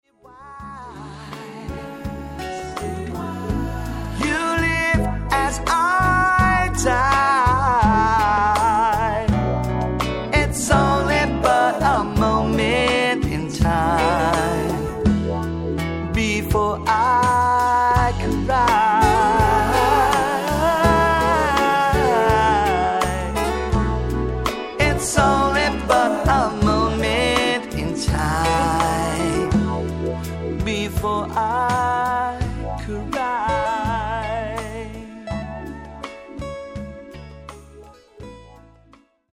フリー・ソウル・バンド